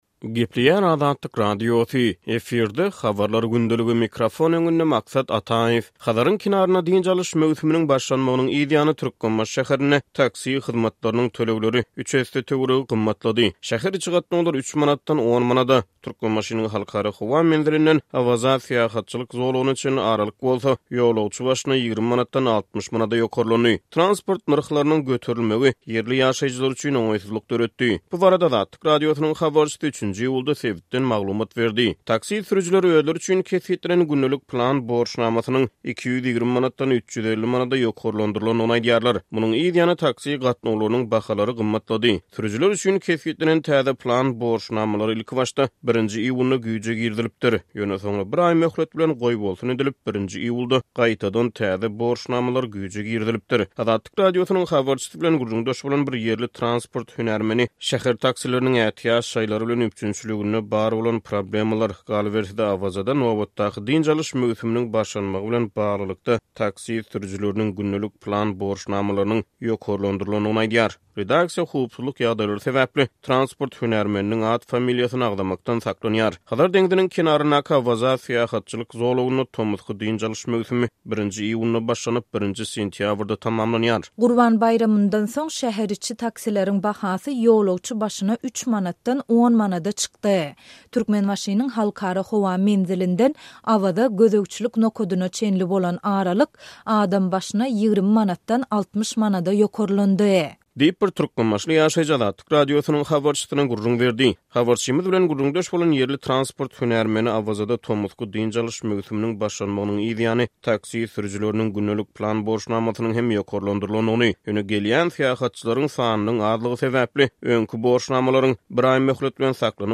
Transport nyrhlarynyň göterilmegi ýerli ýaşaýjylar üçin oňaýsyzlyk döretdi. Bu barada Azatlyk Radiosynyň habarçysy 3-nji iýulda sebitden maglumat berdi.